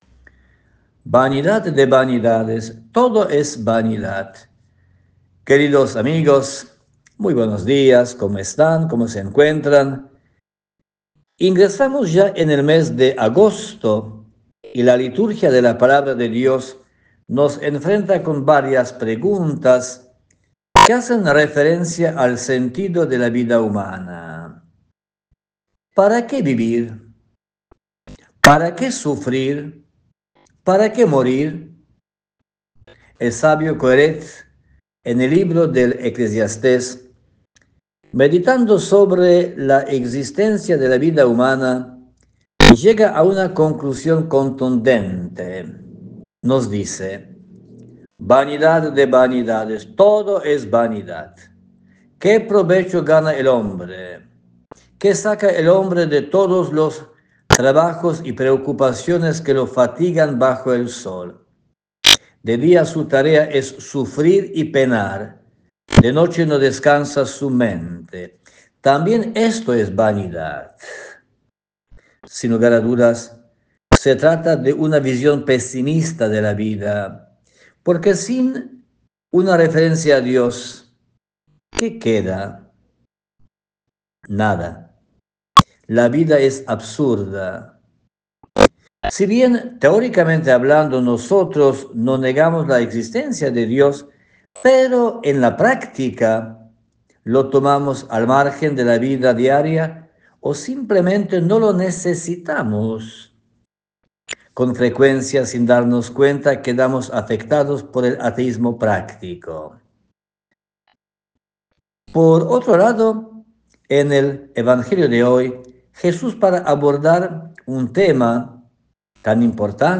comparte cada domingo un breve y reflexivo mensaje en EME